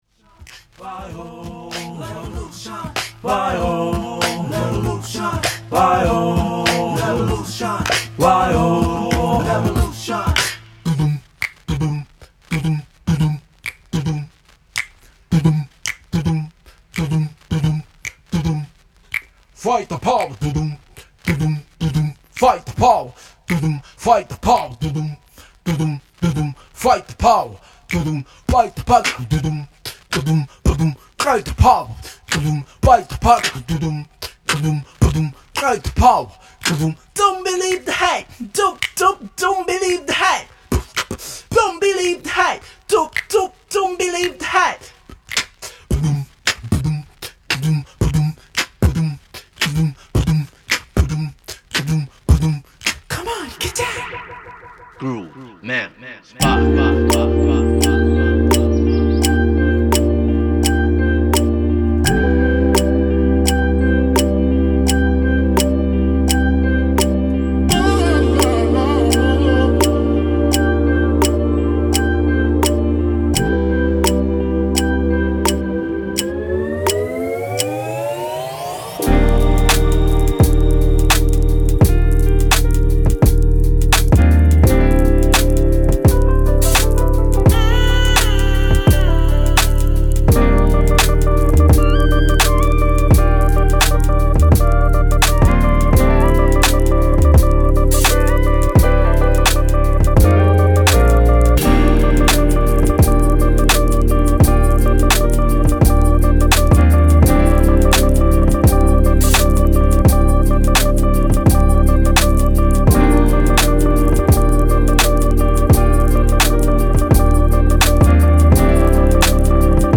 序盤から終盤まで一貫してメロウネスに特化した本作。
リスナー/音楽ファンとの繋がりを意識した、ファットで暖かい質感の音出し。